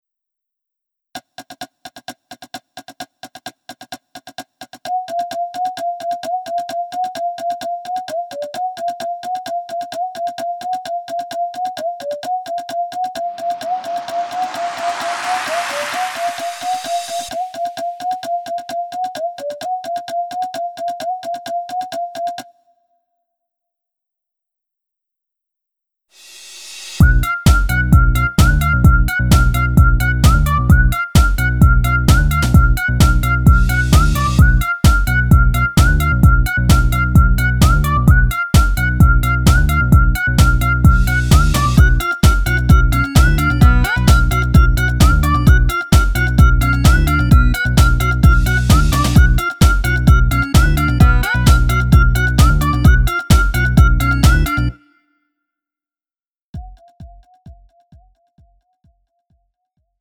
음정 -1키 1:48
장르 가요 구분 Lite MR